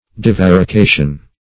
Divarication \Di*var`i*ca"tion\, n. [Cf. F. divarication.]